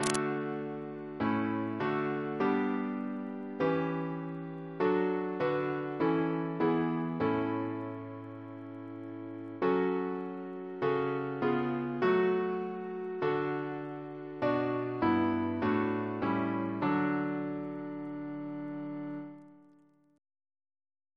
Double chant in D Composer: John Soaper (1743-1794) Reference psalters: ACB: 186; ACP: 297; CWP: 91; H1940: 625; H1982: S183; OCB: 20; PP/SNCB: 41; RSCM: 123